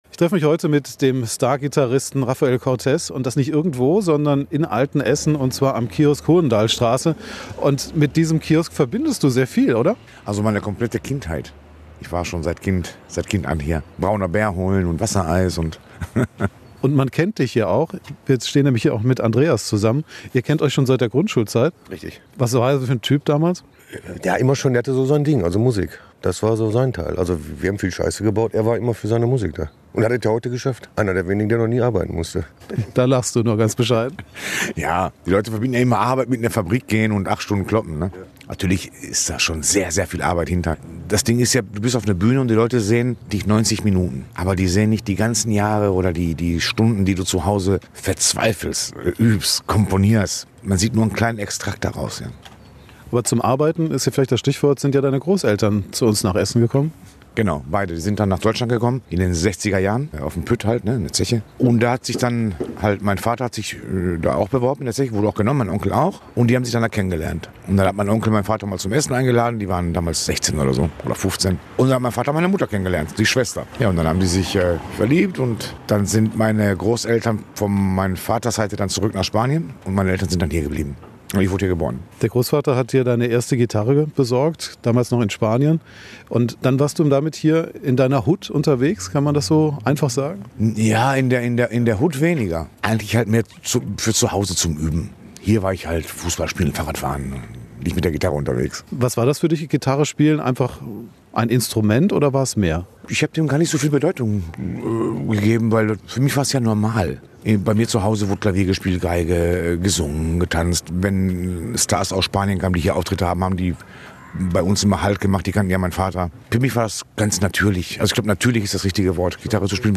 trifft den Star-Gitarristen Rafael Cortes an seiner Lieblingsbude in Altenessen.